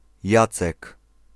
PronunciationPolish: [ˈjat͡sɛk]
Pl-Jacek.ogg.mp3